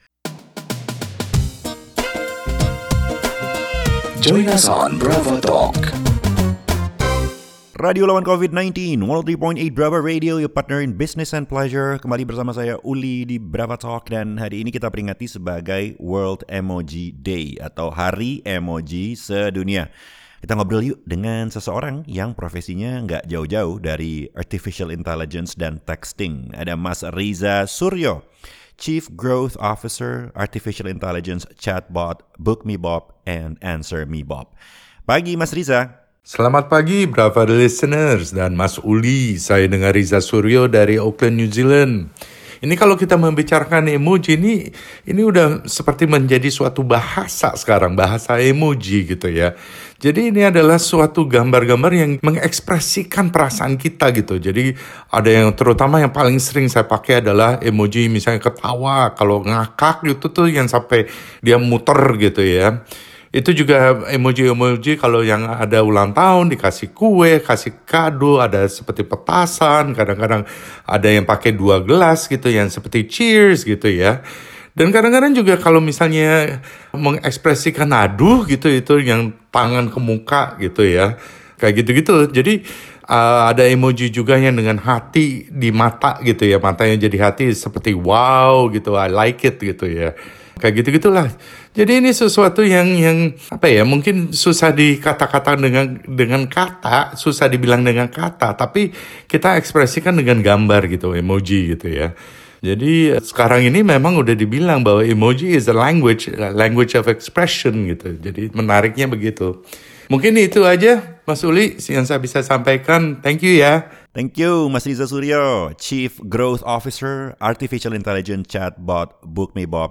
Chief Growth Officer on Jakarta leading radio station “Bravo Talk” prime time 8.00am  last week talking about emoji language on the official “world emoji day” .